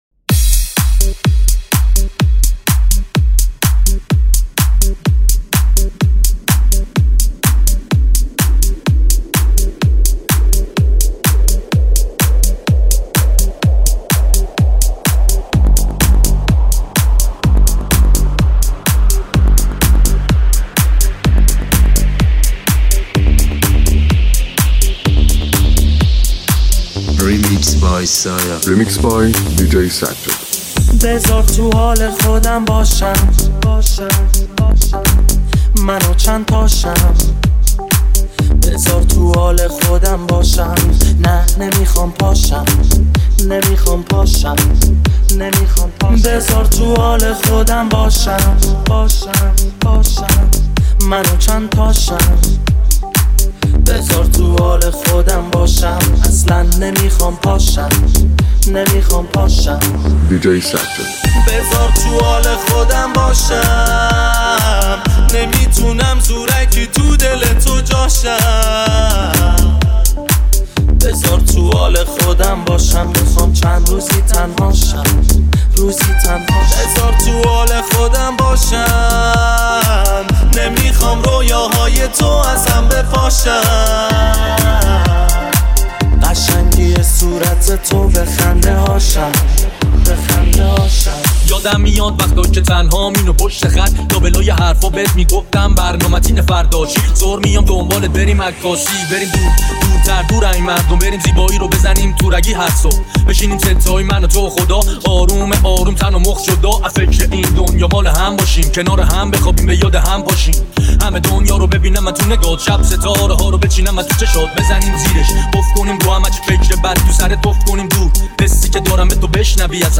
ریمیکس رپی
اهنگ رپ